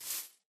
Sound / Minecraft / step / grass4.ogg
grass4.ogg